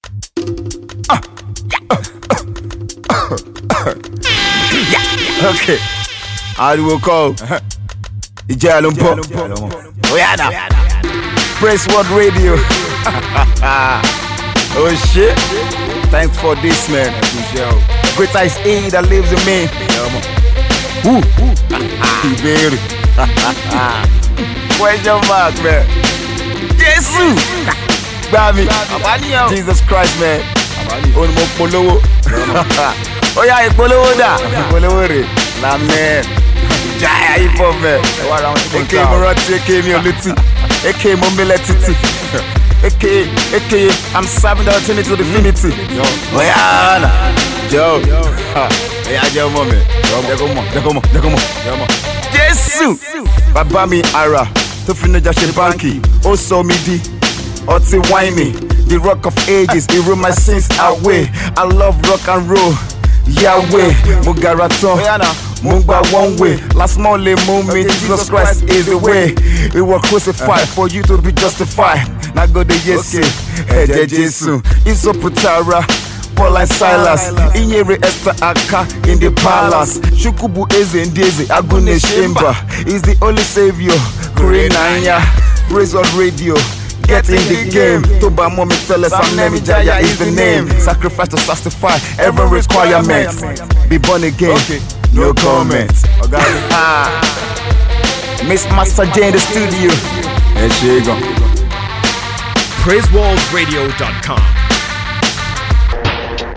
rapper
brings the street flavour
hip hop